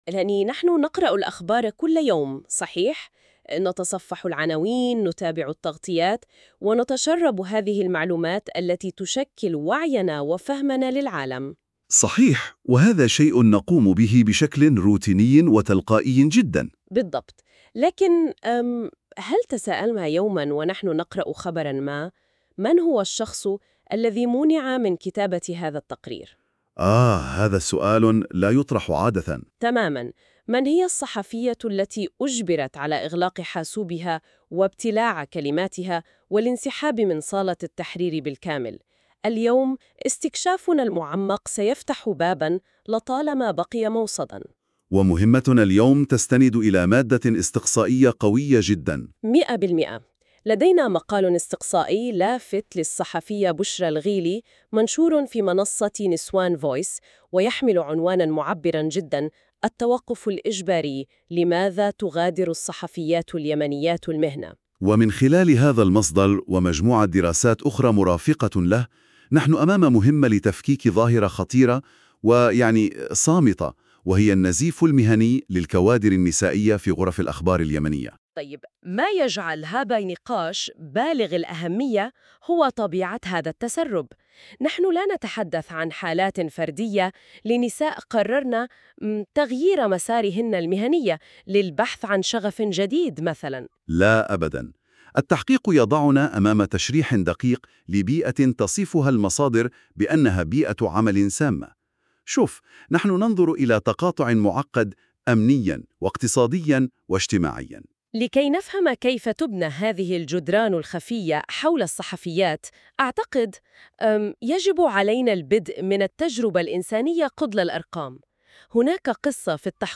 ملخص صوتي للمادة المكتوبة مولد بالذكاء الاصطناعي بواسطة Notebook LM